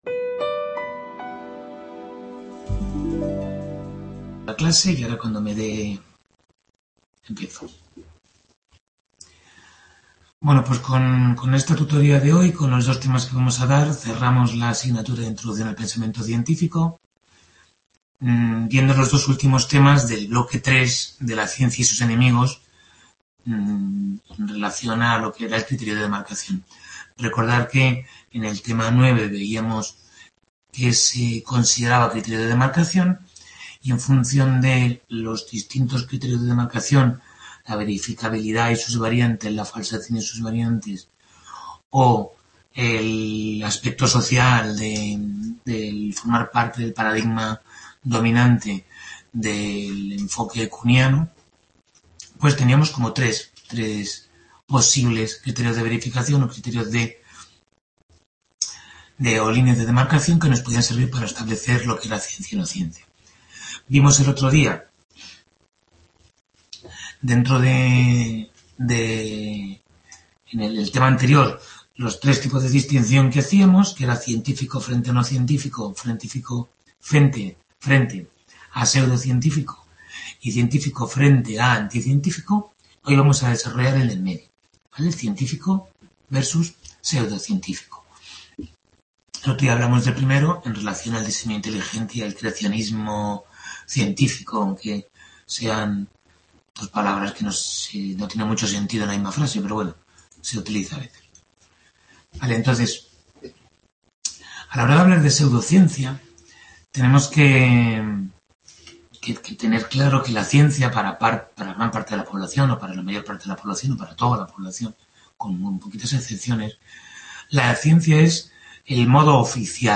Tutoría 12 de la asignatura Introducción al Pensamiento Científico